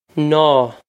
naw
This is an approximate phonetic pronunciation of the phrase.